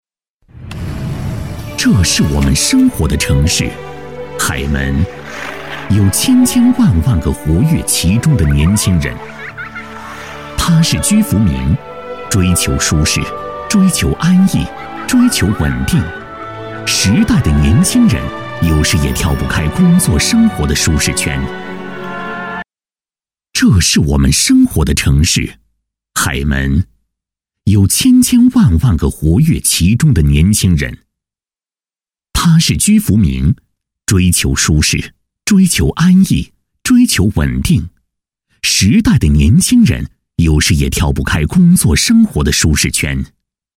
配音风格： 磁性，年轻
【专题】我们生活的城市